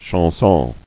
[chan·son]